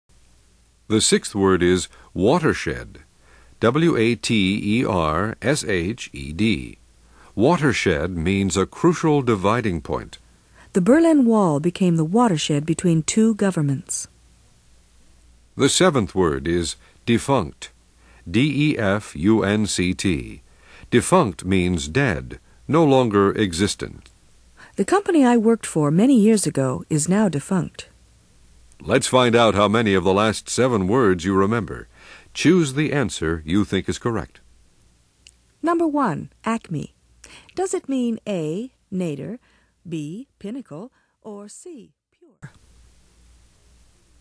Learn 1000s of new words, each pronounced, spelled, defined, and used in a sentence.